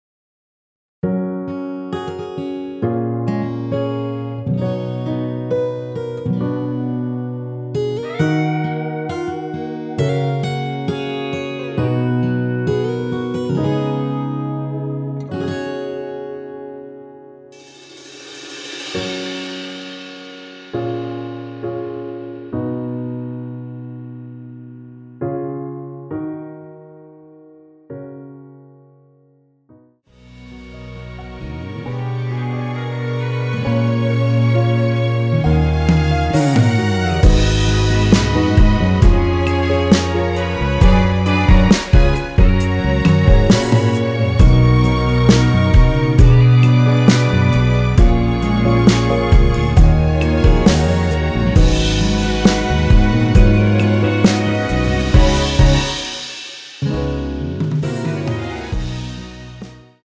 원키에서(+3)올린 MR입니다.
여성분이 부르실수 있는 키로 제작 하였습니다.(미리듣기 참조)
F#
앞부분30초, 뒷부분30초씩 편집해서 올려 드리고 있습니다.
중간에 음이 끈어지고 다시 나오는 이유는